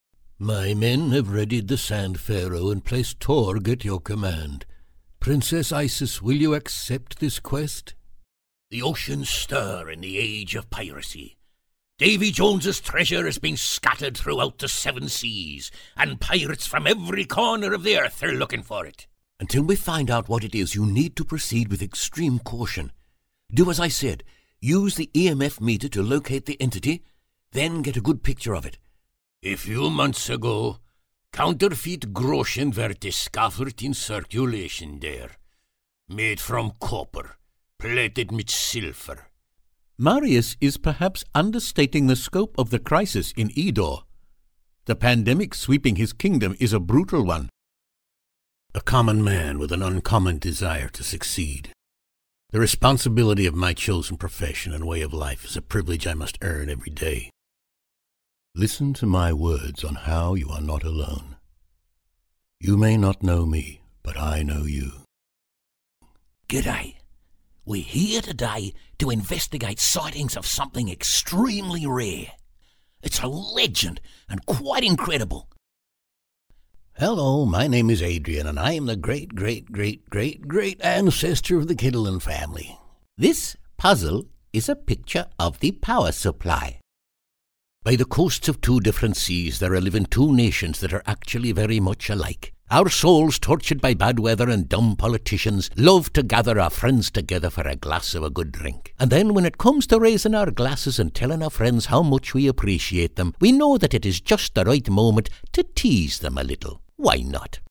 Rode NT-1A Microphone, Focusrite interface.
BaritoneDeepLow